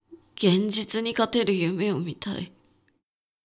ボイス
低音男性
dansei_genzitunikateruyumewomitai.mp3